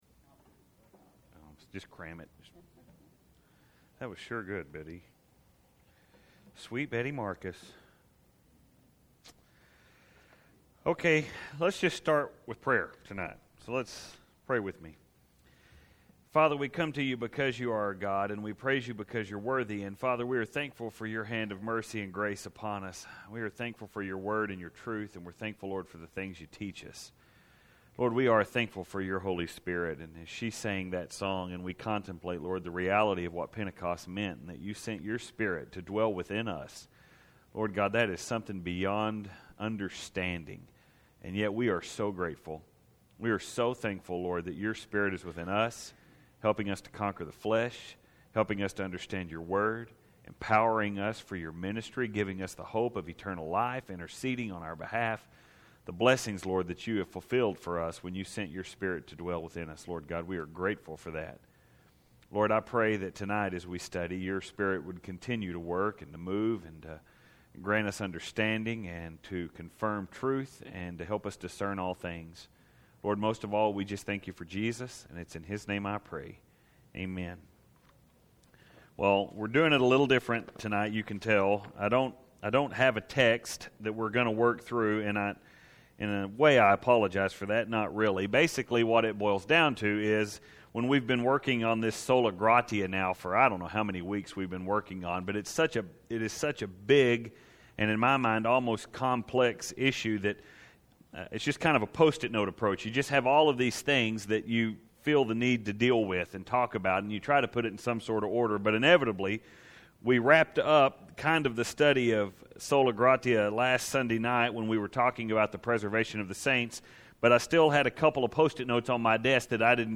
That being said, I’m going to apologize in advance for the random feel to this sermon and particularly that we don’t have a specific text to work through as the basis for it.